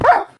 mob / wolf / hurt2.ogg
hurt2.ogg